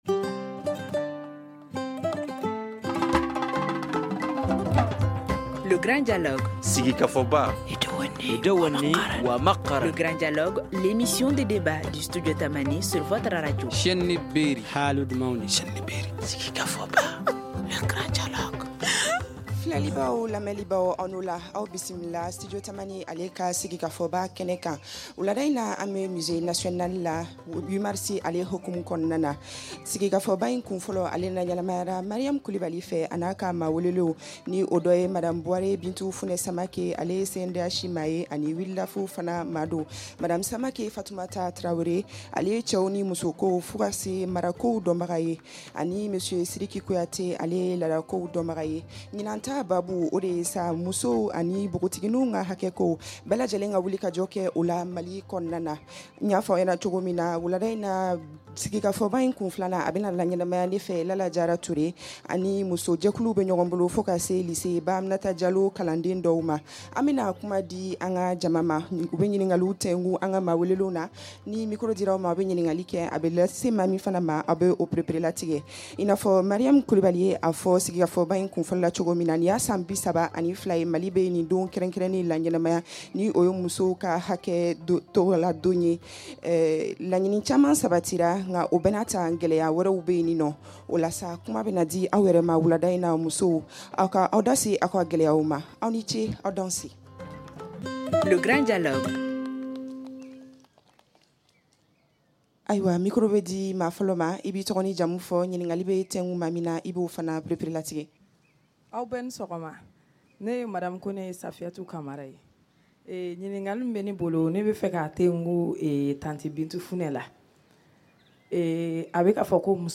2ème partie de votre émission de débat public à l’occasion du 8 mars, journée internationale des droits de la femme.